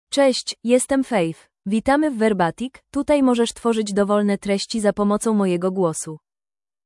Faith — Female Polish (Poland) AI Voice | TTS, Voice Cloning & Video | Verbatik AI
Faith is a female AI voice for Polish (Poland).
Voice sample
Female
Faith delivers clear pronunciation with authentic Poland Polish intonation, making your content sound professionally produced.